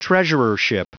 Prononciation du mot treasurership en anglais (fichier audio)
Prononciation du mot : treasurership
treasurership.wav